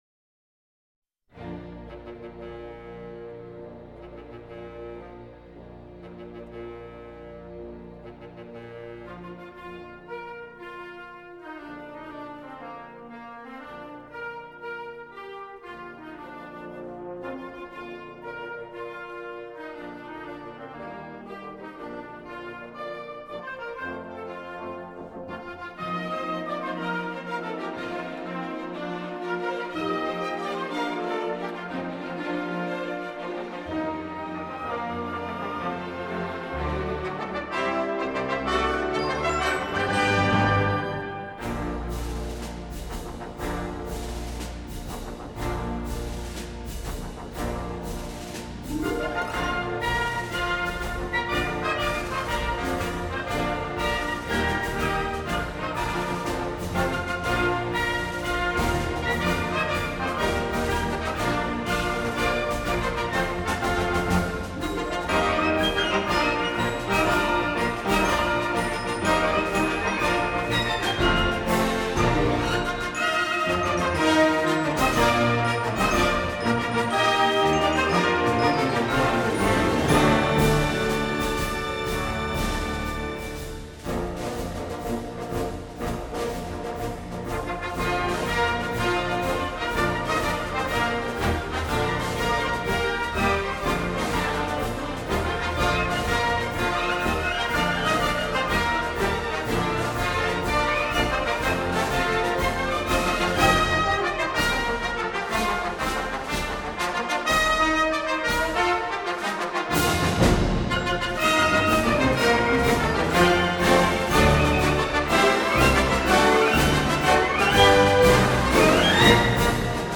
This served as the end title music for the film.